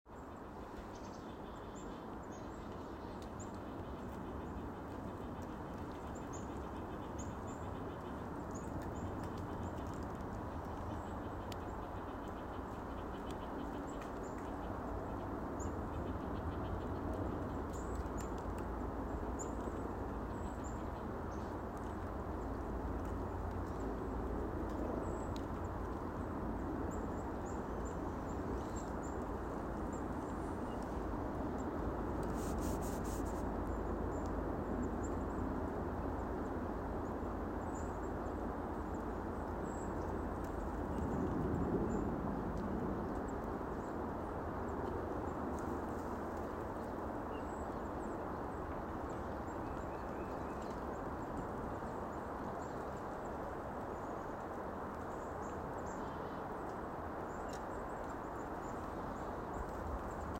Returning to Centennial Woods following the Vernal Equinox, remains of the passing Winter and the budding new Spring were on display.
Frequent and diverse bird calls were heard throughout the woods. The brook’s waters run high and strong due to melted snow running off into the stream.
Link to audio clip of bird calls from my visit